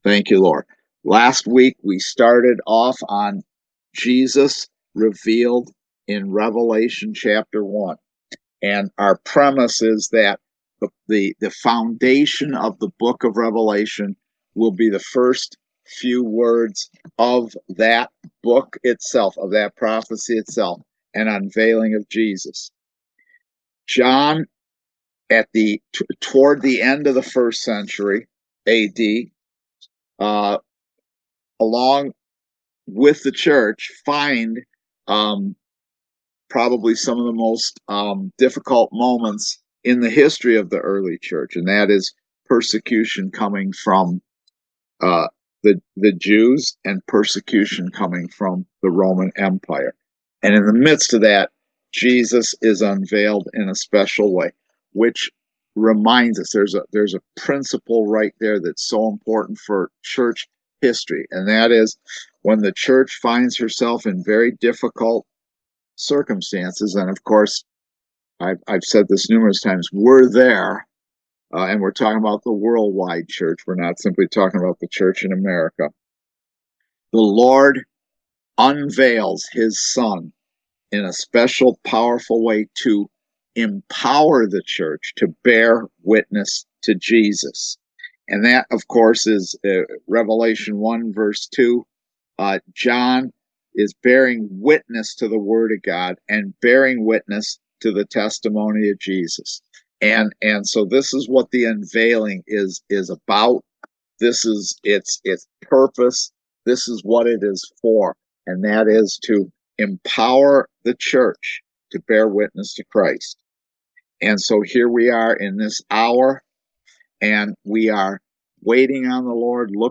Service Type: Kingdom Education Class